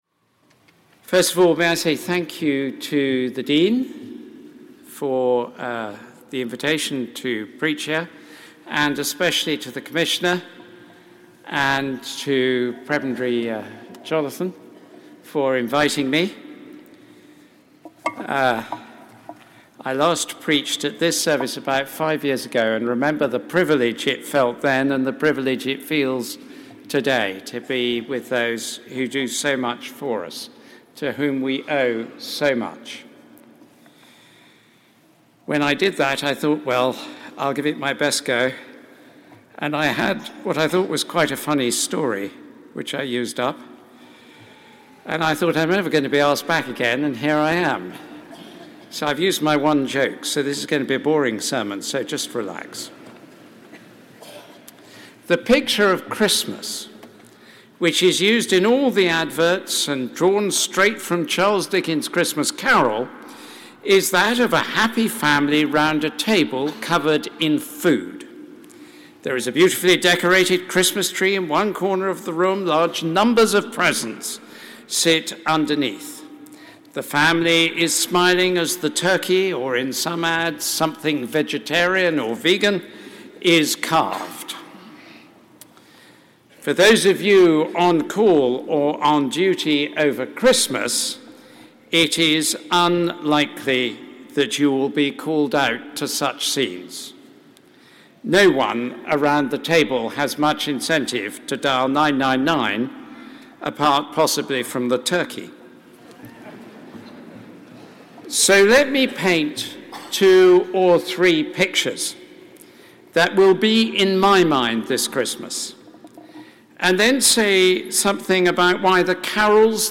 Sermon given by the Archbishop of Canterbury
Sermon given by The Most Reverend and Right Honourable Justin Welby, Archbishop of Canterbury, at the Metropolitan Police Carol Service, 17th December 2019